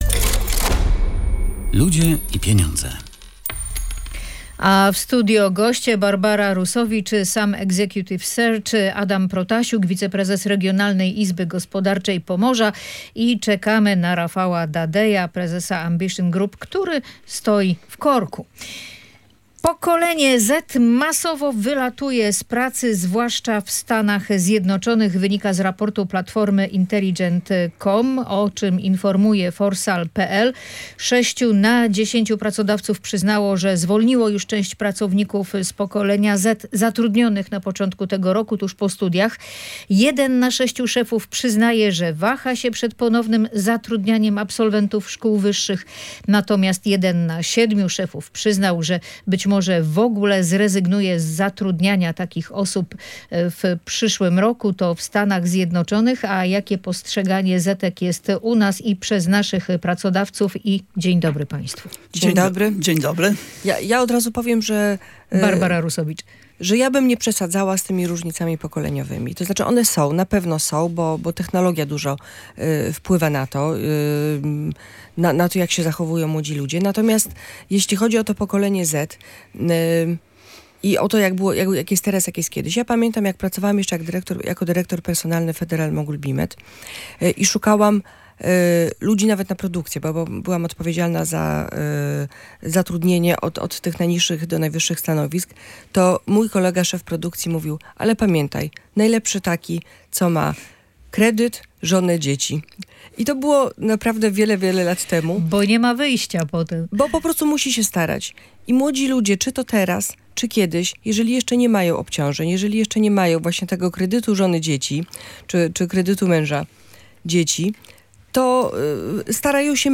Nad tym debatowano w programie gospodarczym „Ludzie i Pieniądze”.